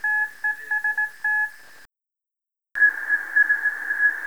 Fichier sonore utilisé pour mesurer la distance Terre-Lune
Enregistrement_selection_echo_FX1A_32_filtered.wav